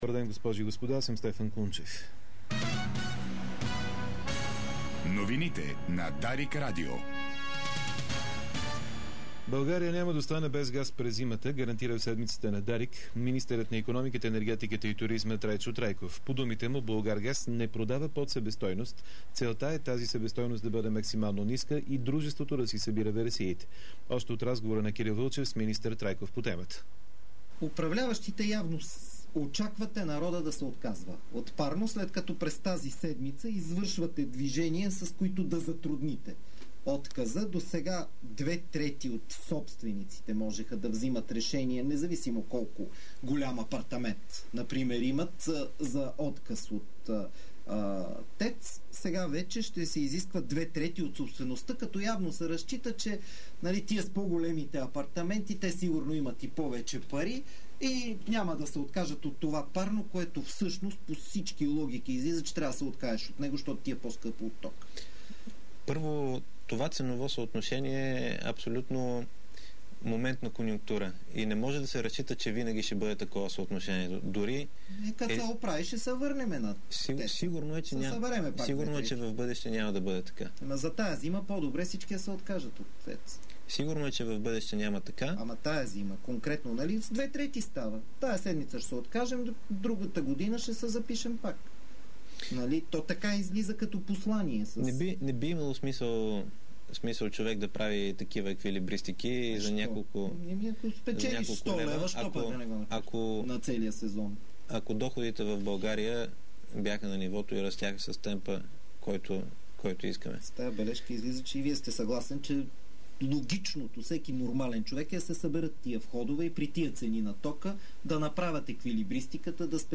Обедна информационна емисия - 03.07.2010